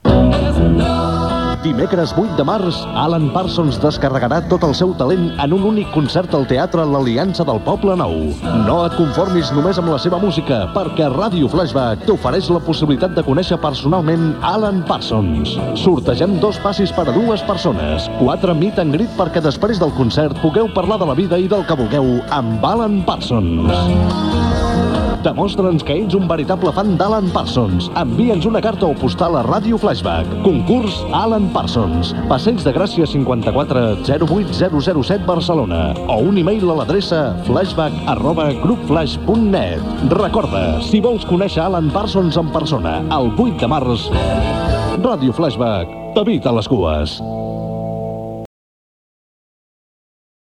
1159f2d9c435cb494f6b5caae047f642a6ee47fe.mp3 Títol Ràdio Flaixbac Emissora Ràdio Flaixbac Cadena Flaix Titularitat Privada nacional Descripció Falca promocional d'un concurs amb motiu del concert d'Alan Parsons.